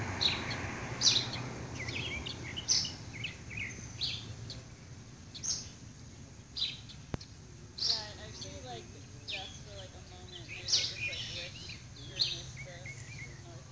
Yellow-green Vireo,  Vireo flavoviridis